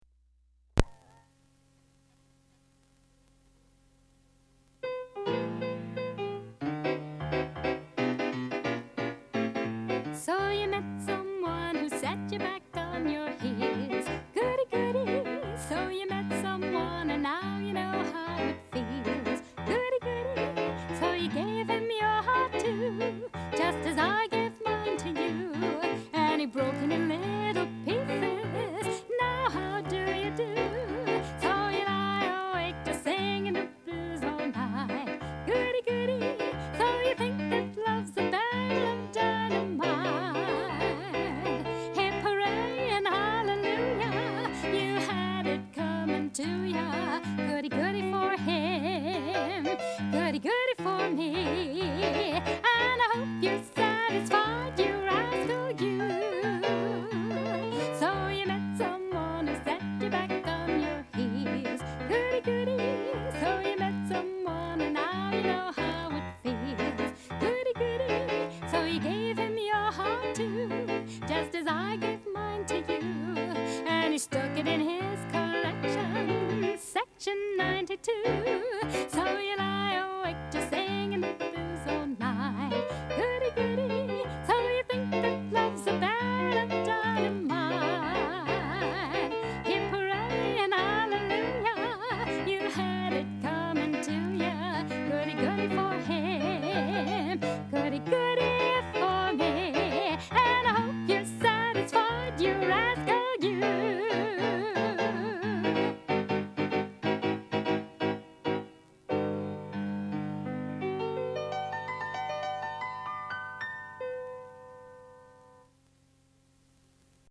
Vocal Selections